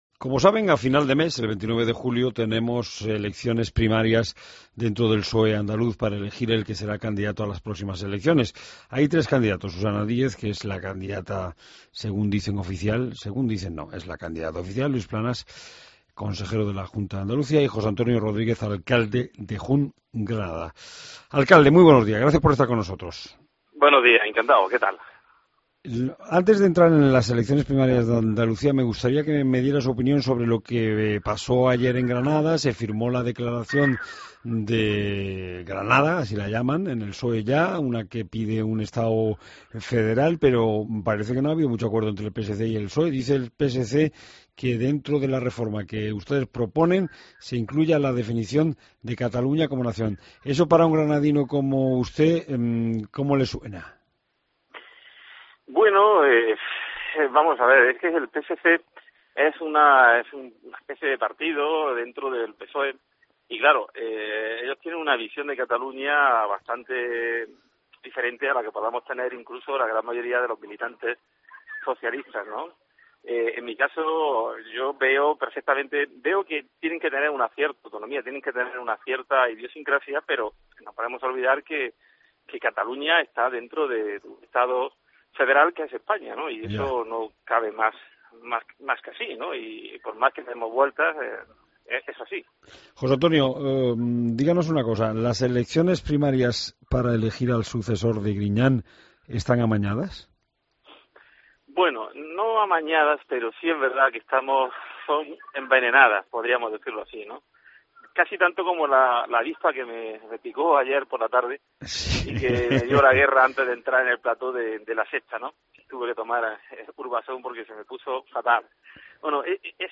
Entrevista al alcalde de Jun, José Antonio Rodríguez